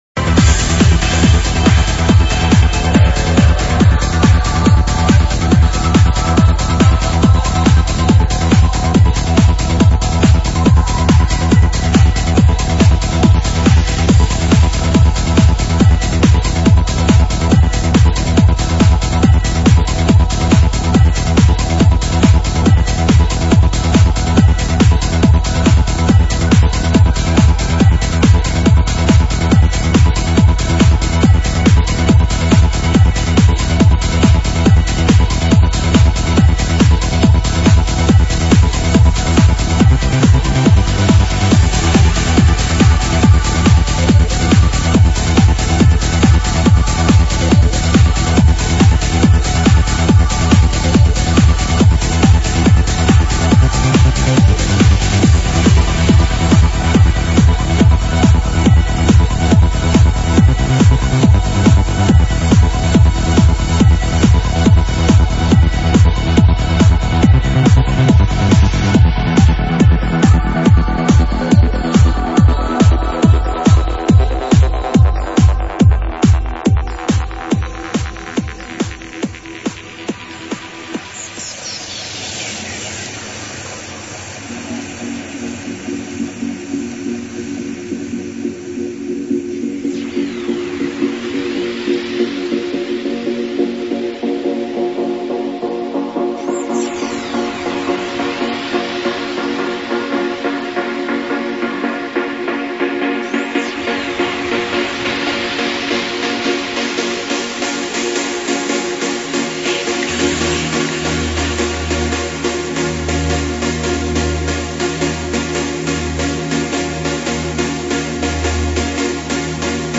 *** Progressive Trance ***